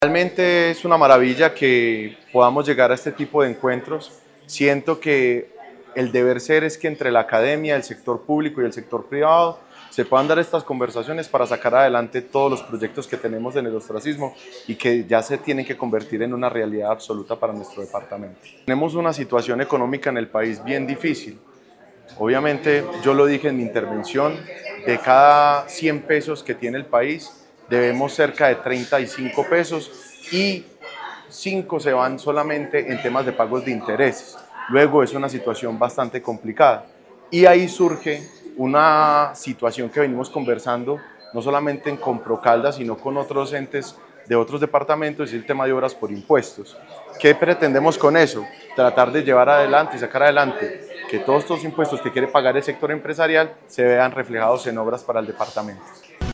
Con un mensaje de articulación y trabajo conjunto, la Secretaría de Planeación de la Gobernación de Caldas lideró el encuentro estratégico ‘Caldas Marca la Ruta: una visión de territorio para los próximos años’, un espacio que reunió a la institucionalidad, el sector privado, la academia y la bancada parlamentaria electa para construir una agenda común que proyecte al departamento en el escenario nacional.
Mateo Hidalgo Montoya, Representante a la Cámara Electo.